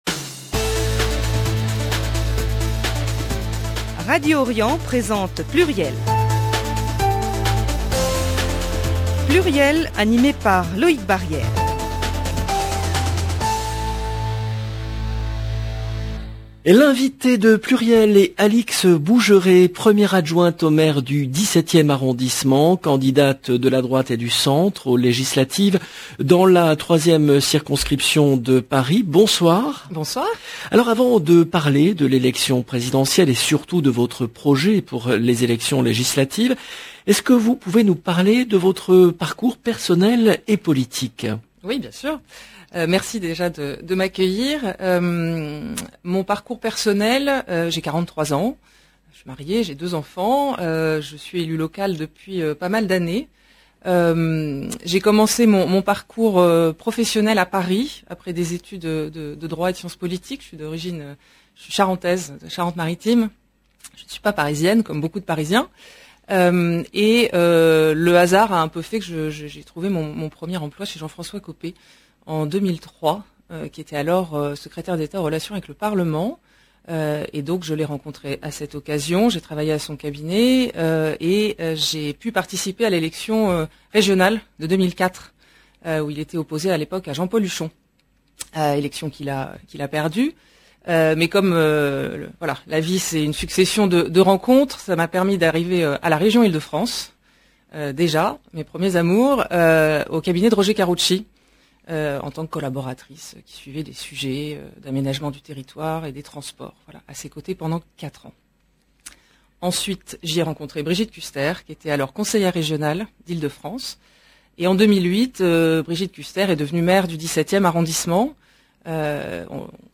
le rendez-vous politique du lundi 4 avril 2022 L'invitée de PLURIEL est Alix Bougeret, 1ere adjointe au maire du 17e arrondissement de Paris, candidate de la droite et du centre aux législatives dans la 3e circonscription de Paris.